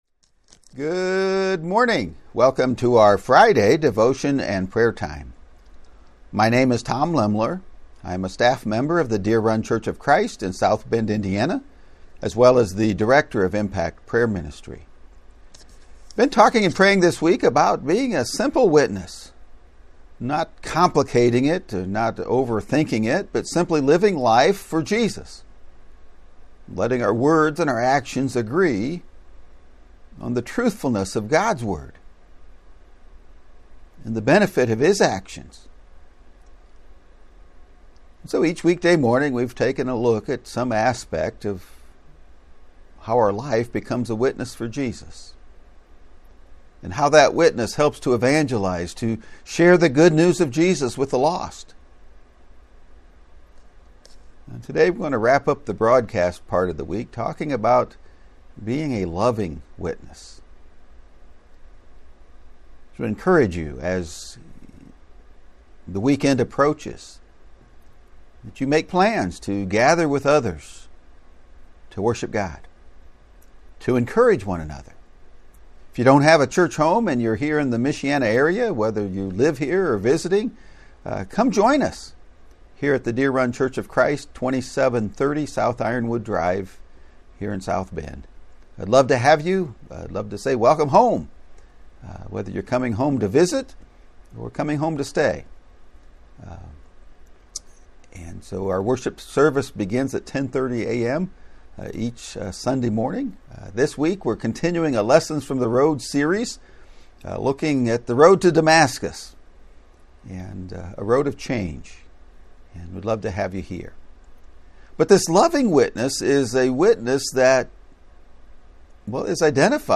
In prayer